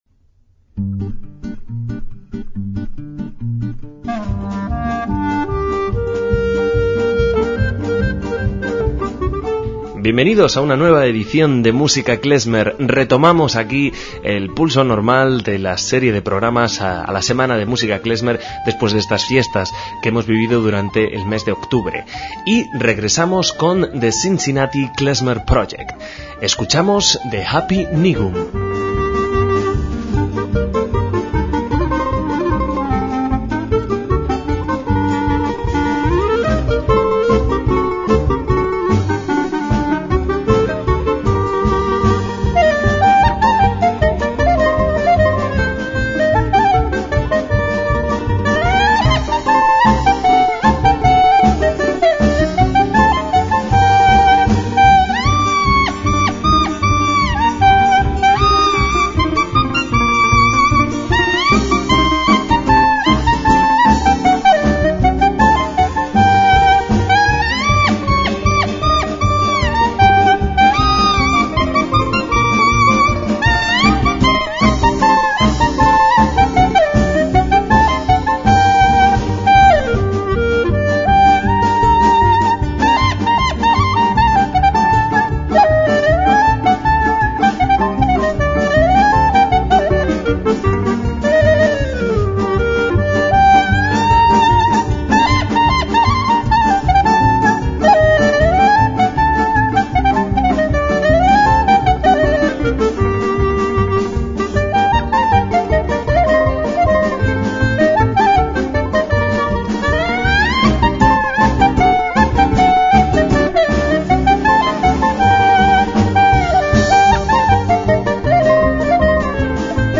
MÚSICA KLEZMER - Cincinnati Klezmer Project es un grupo que, desde 1993, interpreta música judía ashkenazó y que ha participado en muchos festivales, así como en celebraciones personales.